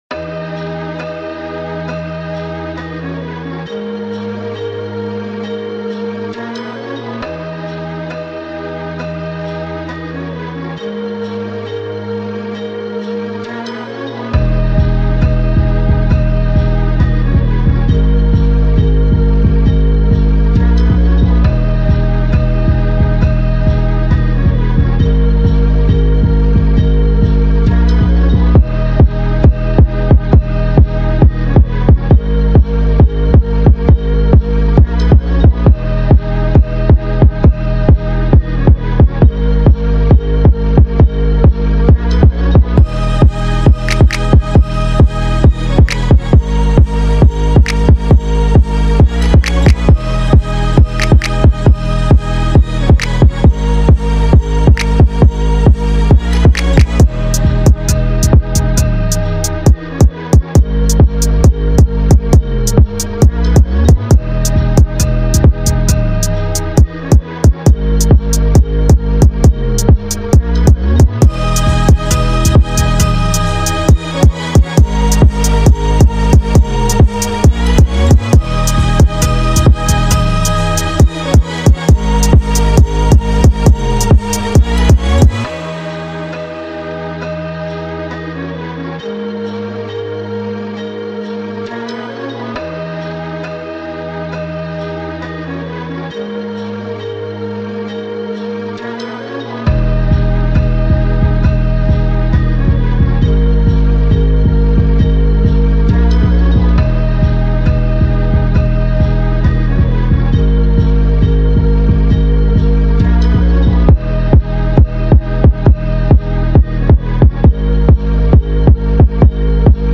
This is the official instrumental
2024 in New Jersey Club Instrumentals